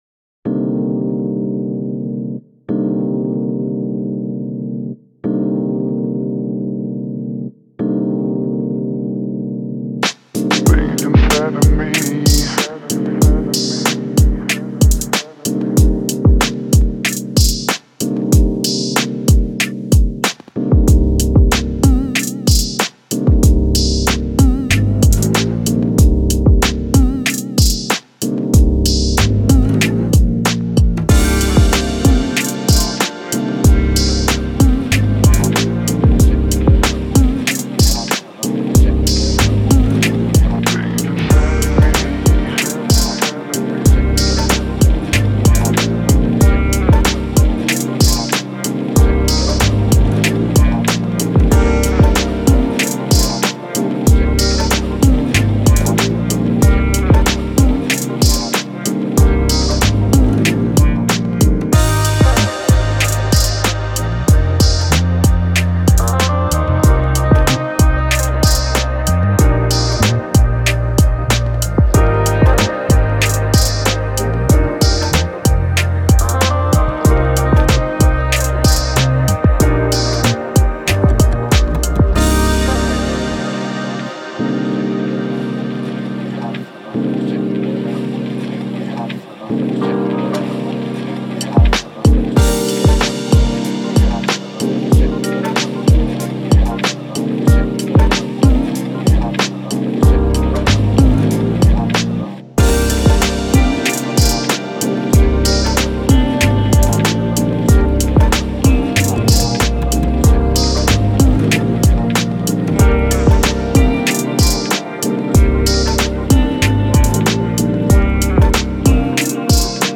Ничего особенного , искал грув и вайб , чтоб музыка работала и создавала настроение.